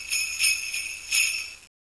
knife_deploy1.wav